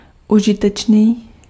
Ääntäminen
IPA : /ˈhæn.di/